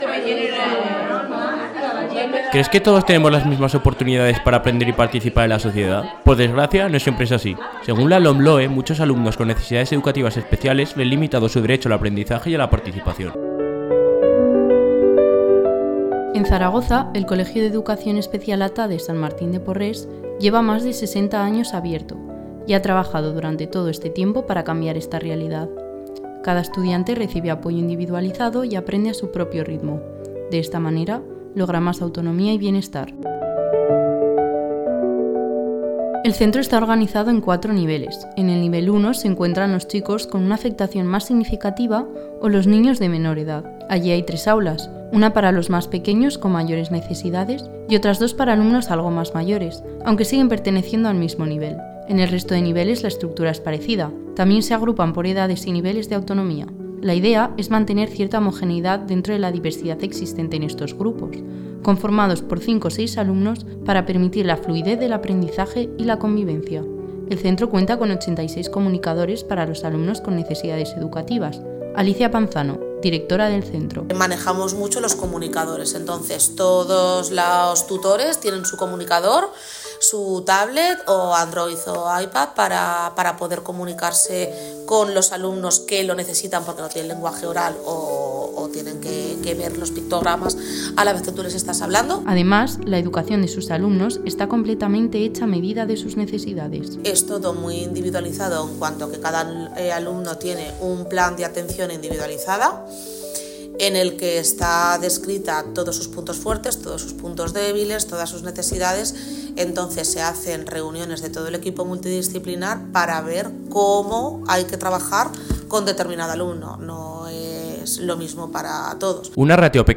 Se trata de un reportaje sobre el centro de ATADES San Martín de Porres, ubicado en Zaragoza. Este reportaje busca ofrecer visibilización a los centros de necesidades especiales y mostrar los problemas que sufren en el día a día. A través de la realización de un reportaje radiofónico, queremos acercar la realidad de este centro a todo aquel que escuche el proyecto.